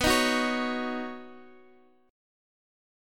Badd9 chord